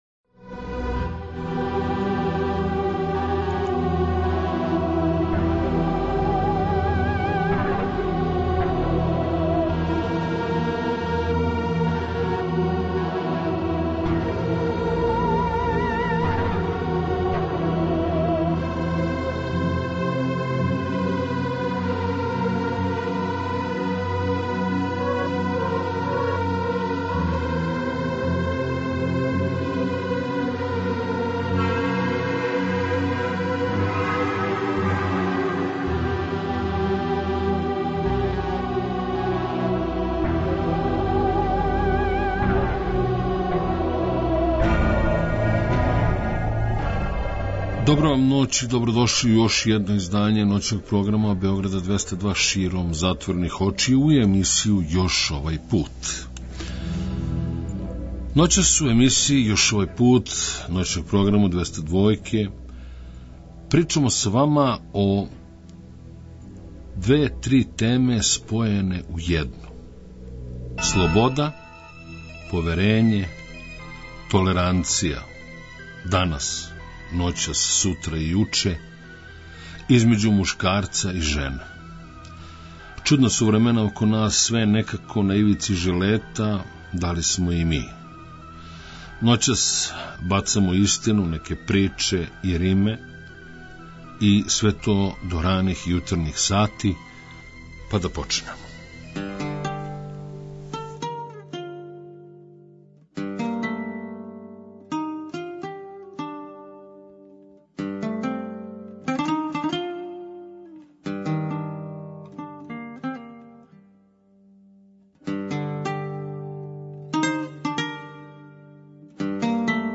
Ноћас, у емисији Још Овај Пут ноћног програма Београда 202 причамо са Вама о слобoди, поверењу, толеранцији између жене и мушкарца.